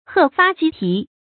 鶴發雞皮 注音： ㄏㄜˋ ㄈㄚˋ ㄐㄧ ㄆㄧˊ 讀音讀法： 意思解釋： 鶴發：白發；雞皮：形容皮膚有皺紋。